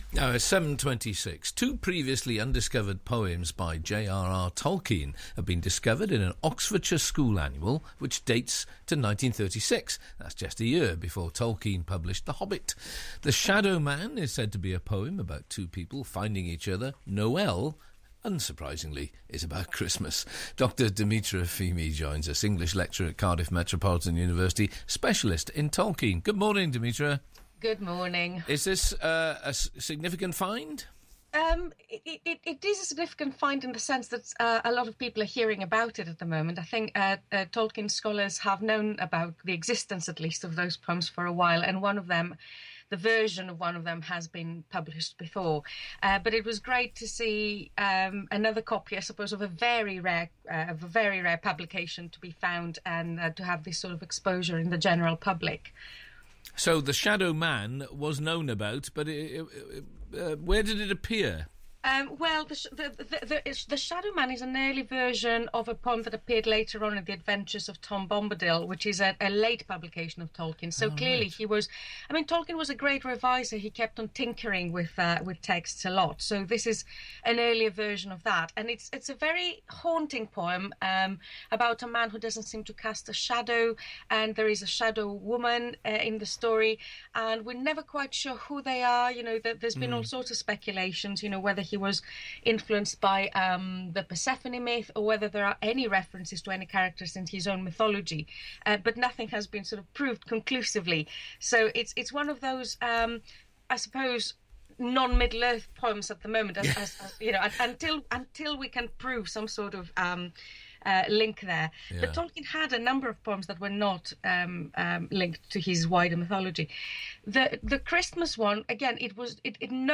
I discussed both poems early this morning in this brief interview on BBC Radio Wales for the Good Morning Wales programme: